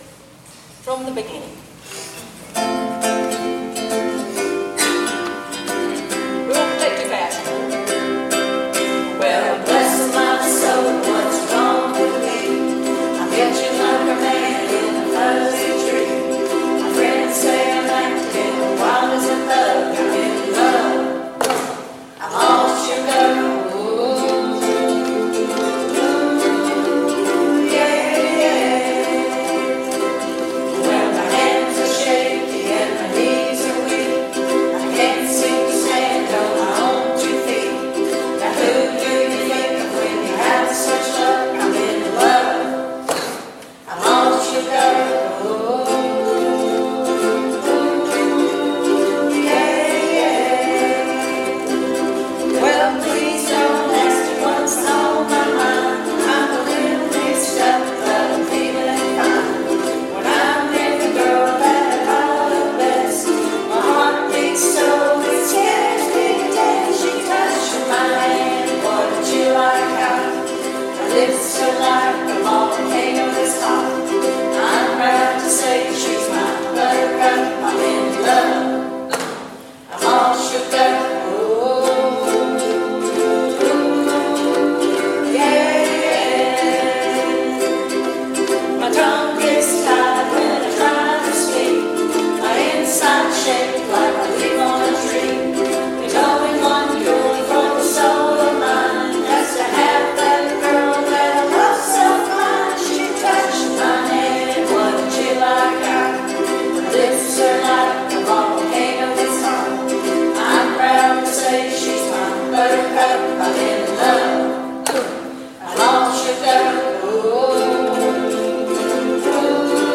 (C)